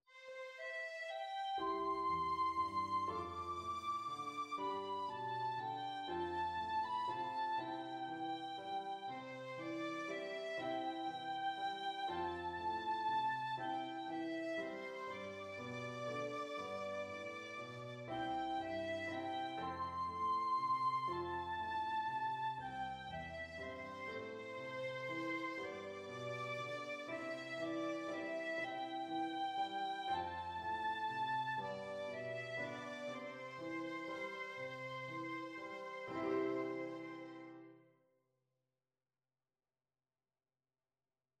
= 120 Slow one in a bar
3/4 (View more 3/4 Music)